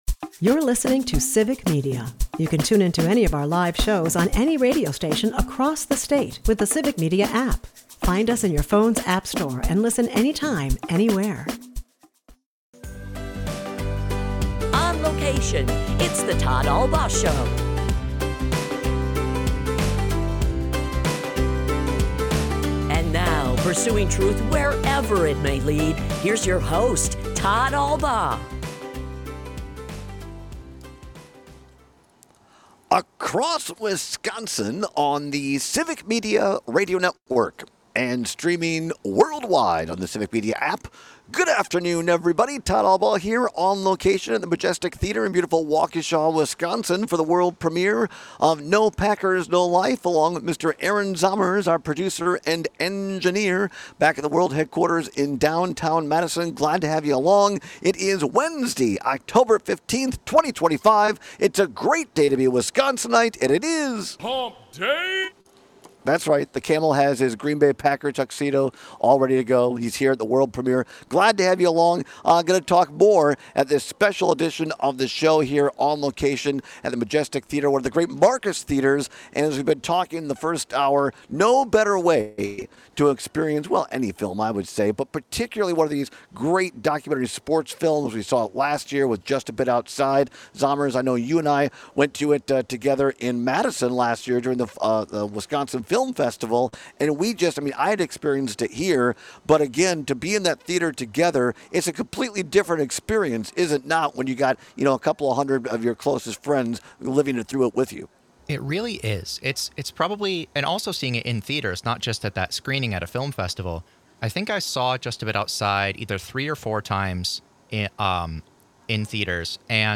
continues his show from the VIP world premiere of No Packers, No Life at the Marcus Majestic Cinema in Waukesha.